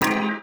UIClick_Menu Select Synth Strong 02.wav